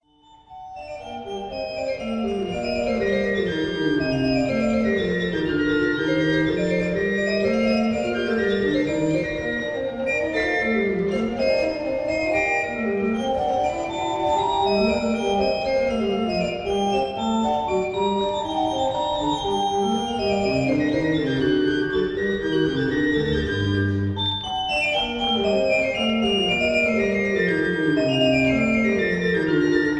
Menuet
Concerto (allegro)
organ, St John’s Church,
Woverhampton